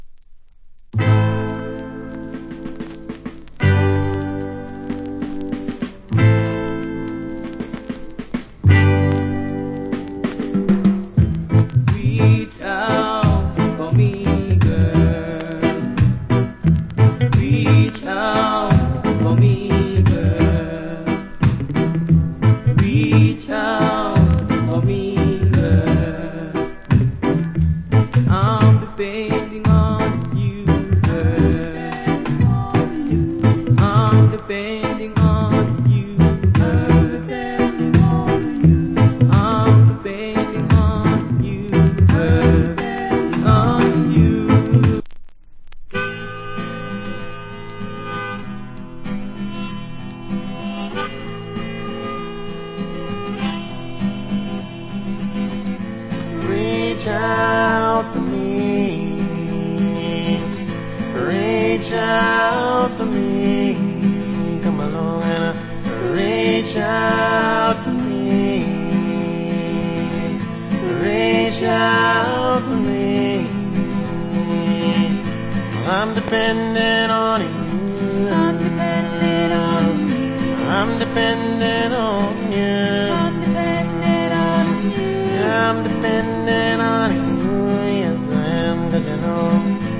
Wicked Rock Steady Vocal.